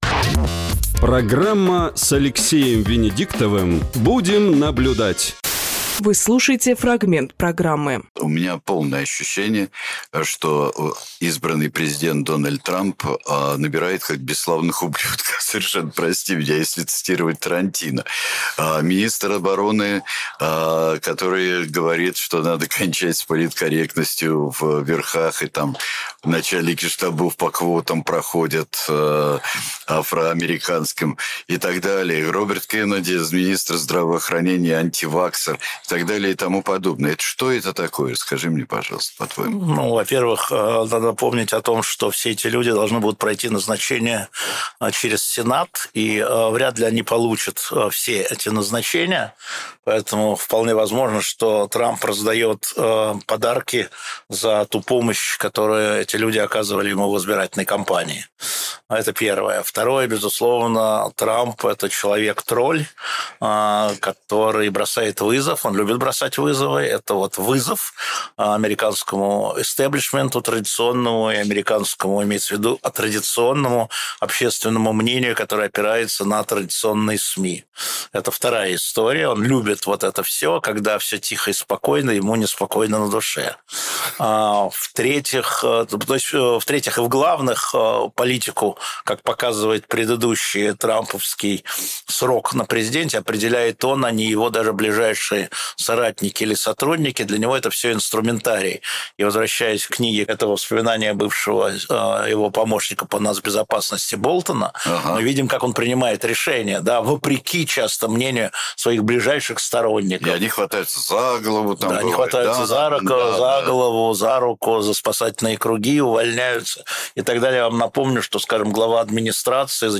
Фрагмент эфира от 17.11.24